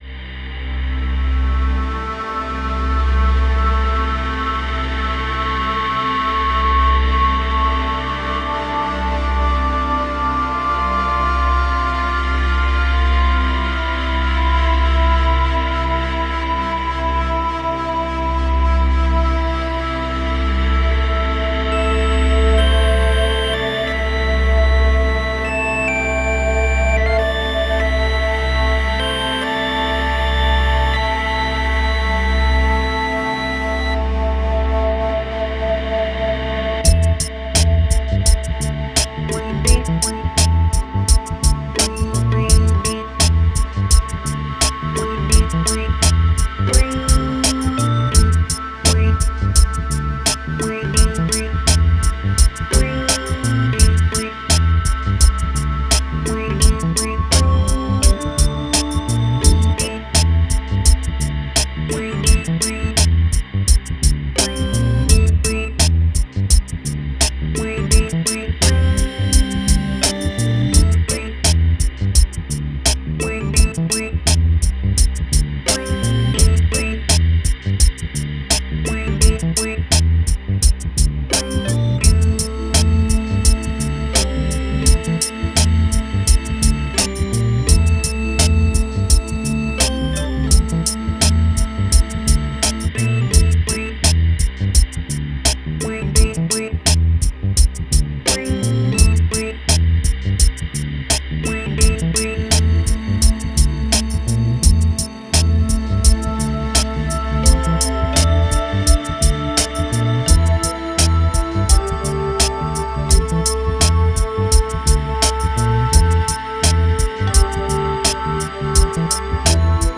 Spherical Soundtrack with Synths and Organ.